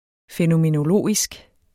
Udtale [ fεnomenoˈloˀisg ]